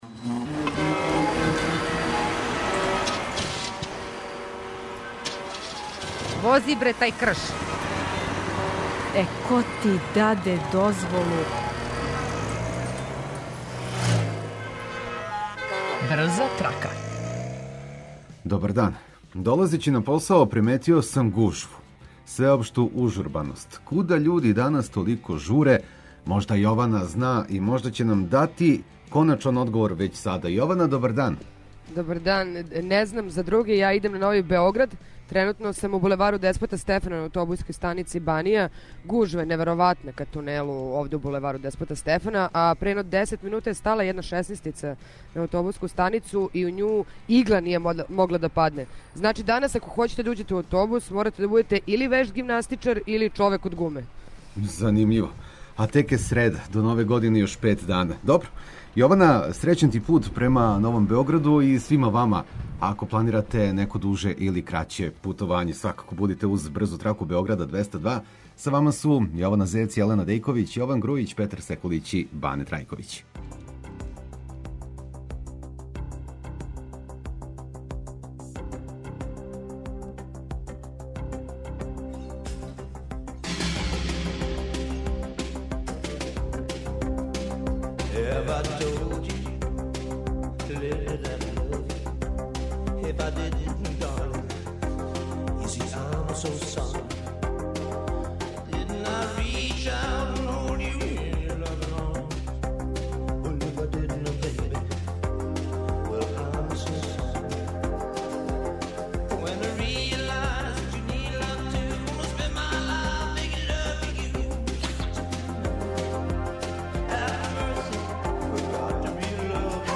Слушаоци репортери јављају новости из свог краја, па нам се јавите и ви.